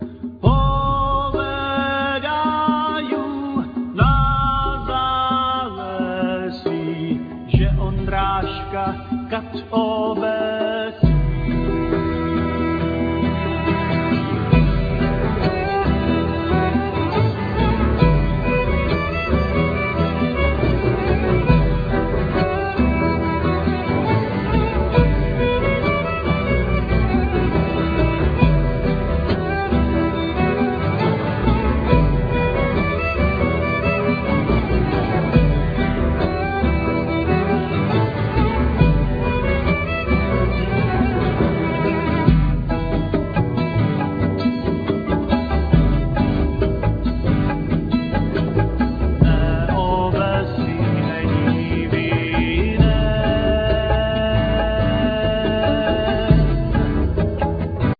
Cena,Flute,Zither
Vocals,Mandolin,Grumle
Violin,Viola
Percussions,Zither,Cymbal
Double-bass
Clarinet
Bell,Percussions